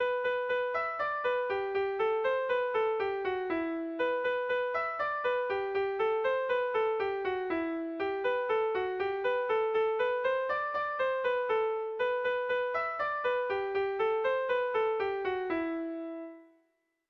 Erlijiozkoa
Zortziko ertaina (hg) / Lau puntuko ertaina (ip)
AABA